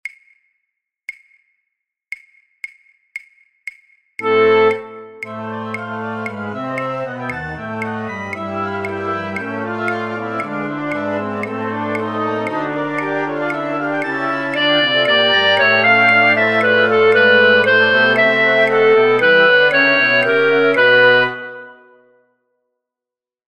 alle partijen